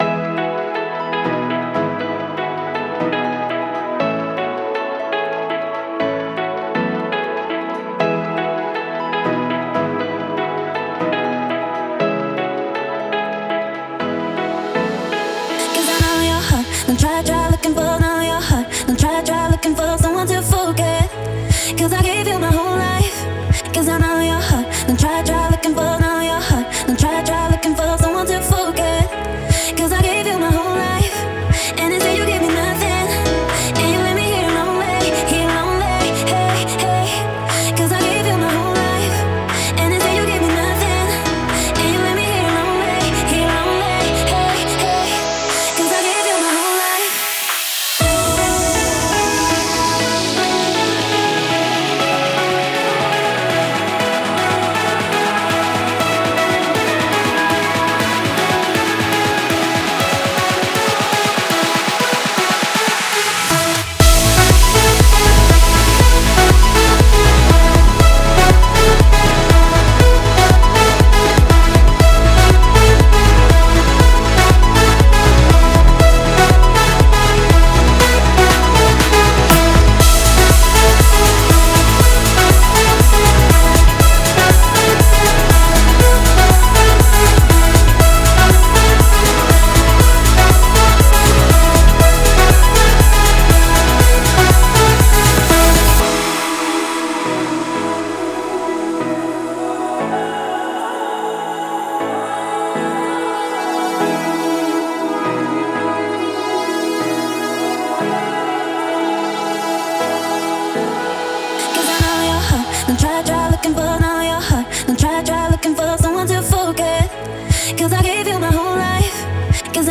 это энергичная электронная композиция